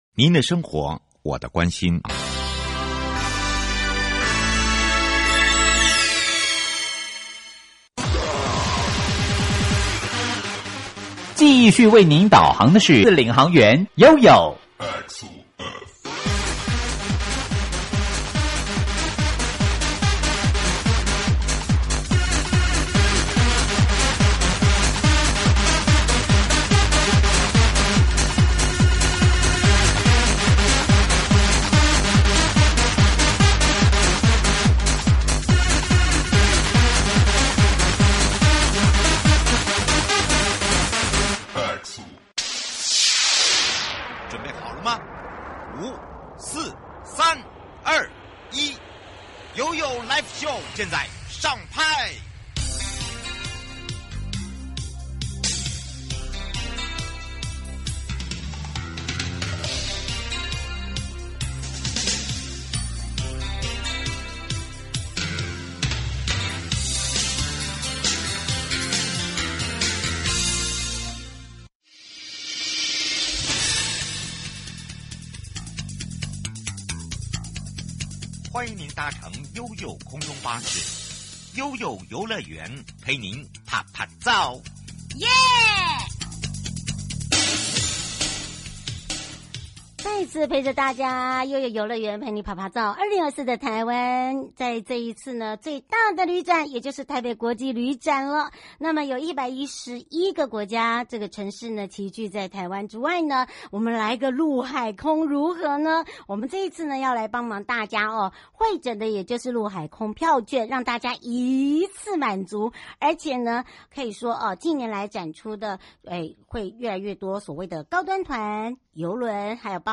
節目內容： 2024 ITF台北國際旅展 優惠全攻略集結111個國家城市展出 探索世界即刻啟程~不容錯過 受訪者：